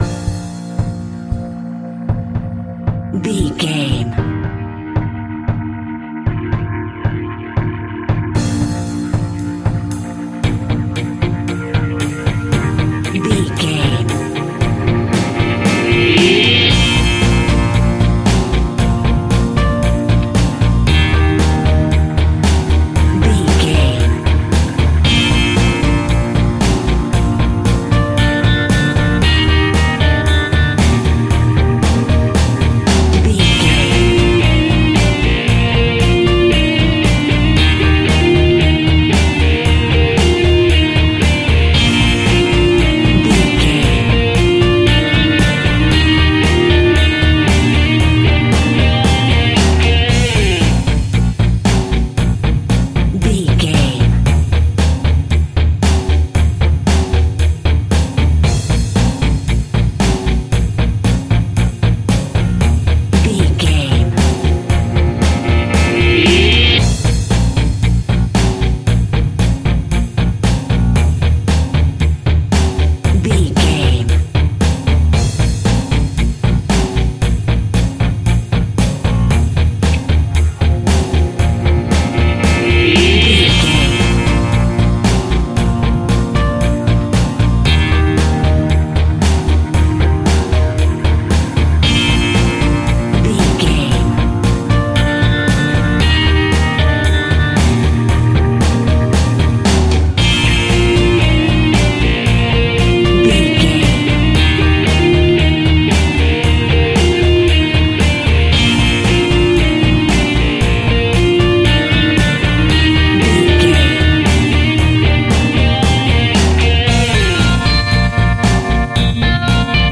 Uplifting
Ionian/Major
B♭
pop rock
indie pop
energetic
cheesy
guitars
bass
drums
piano
organ